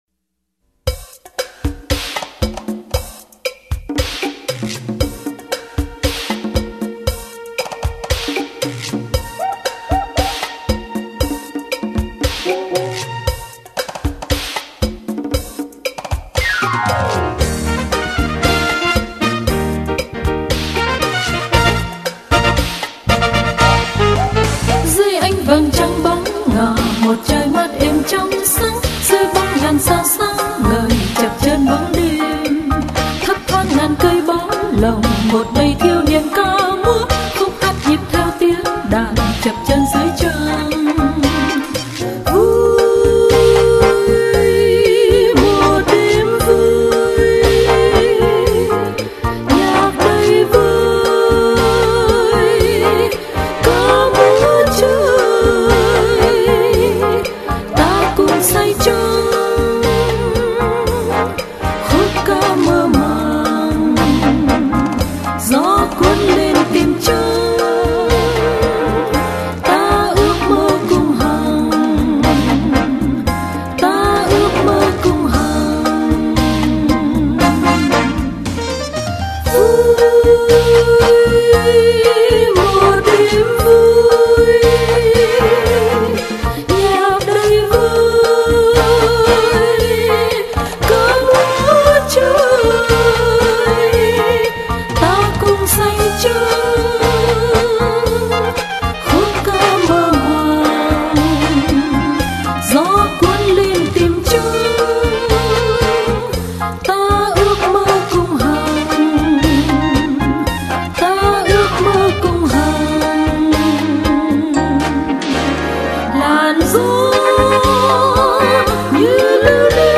Trình bày: Mai Hương
Chúng tôi sẽ cố sưu tập bản nhạc được hát bởi một ca sĩ miền Nam trước 1975 để ý nghĩa bảo tồn được trọn vẹn, dù rằng cũng bản nhạc đó, với phần kỹ thuật, phối âm , phối khí và ca sĩ trẻ hơn thực hiện tại hải ngọai sau này có hay hơn nhiều.